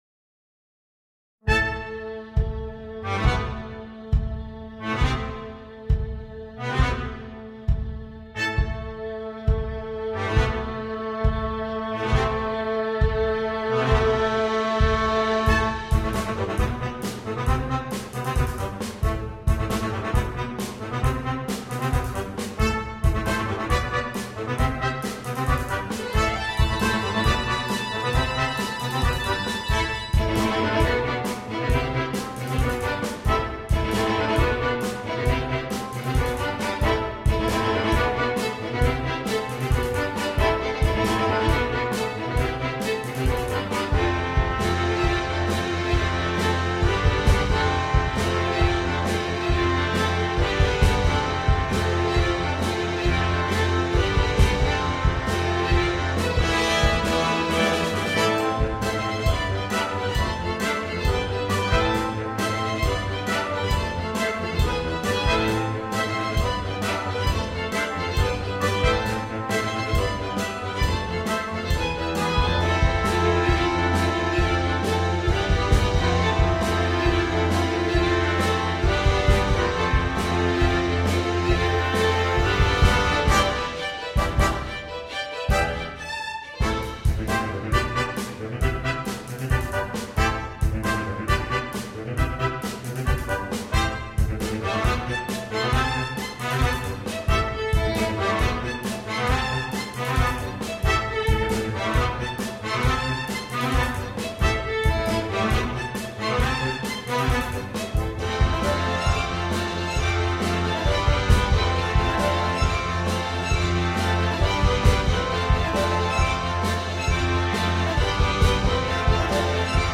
для брасс-бэнда и скрипки.